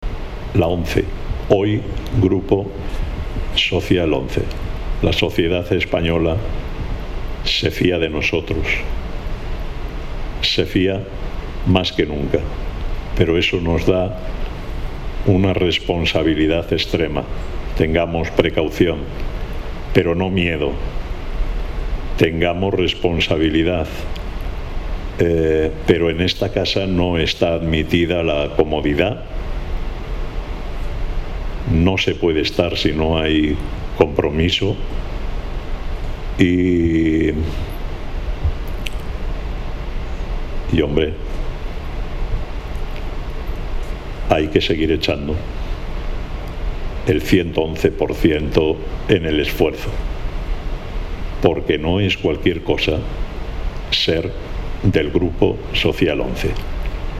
dijo formato MP3 audio(1,09 MB)Lo hizo durante la clausura del último Comité de Coordinación General (CCG) ordinario, celebrado presencial y telemáticamente el  9 y 10 de junio en Madrid, que reunió a más de 200 personas, responsables de todos los equipos de gestión de toda España.